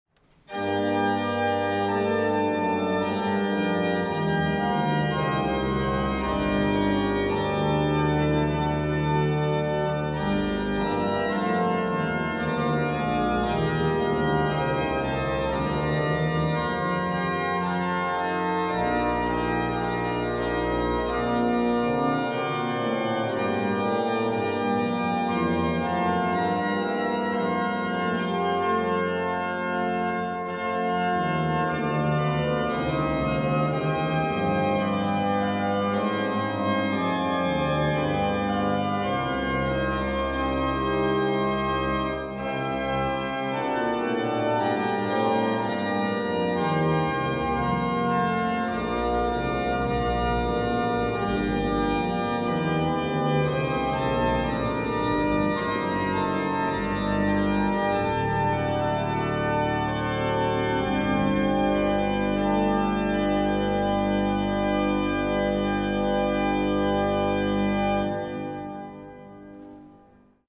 Concert sur l'orgue Aubertin de l'église Saint-Louis à Vichy
Les extraits montrent quelques échantillons des sonorités particulières de l'orgue.